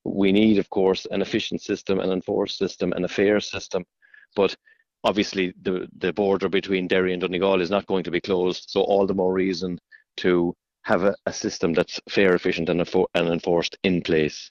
Sinn Fein’s Justice Spokesperson Pa Daly says the Government’s system on migration must be robust: